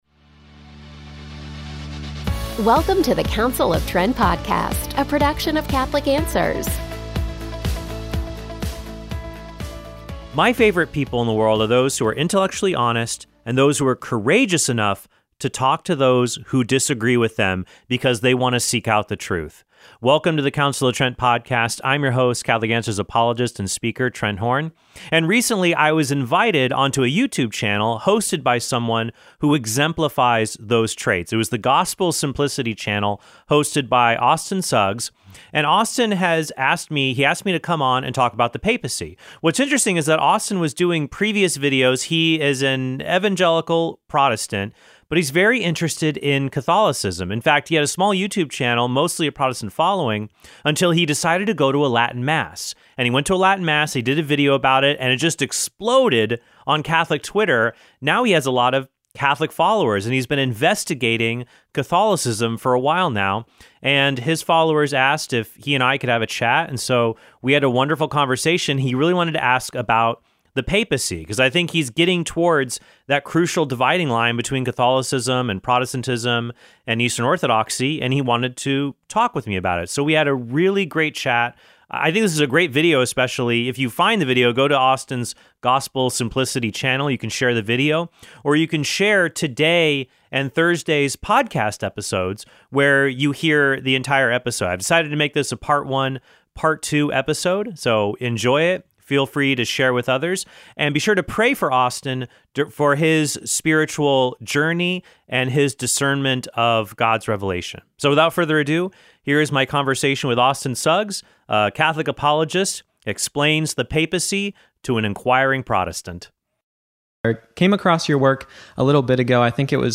A Catholic apologist explains the papacy to an inquiring Protestant.